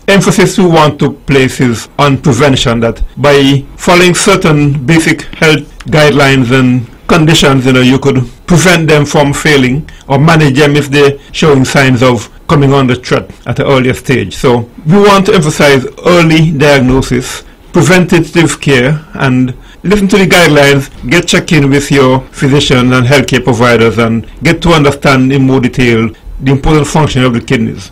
During Tuesday’s edition of the “Let’s Talk” program, members of the Nevis Renal Society spoke on their current campaign to educate the public.